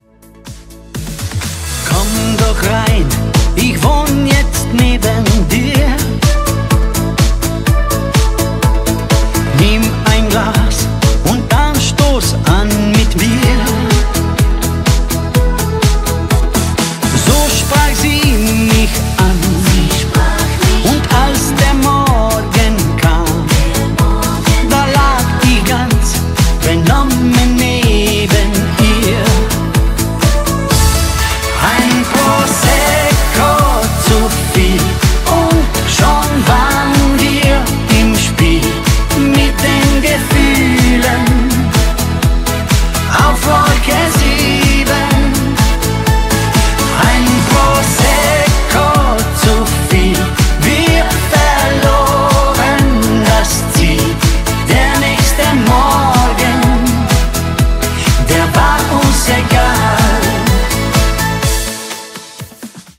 Volkstümlich / Oberkrainer 100% LIVE
• Coverband
• Tanzbands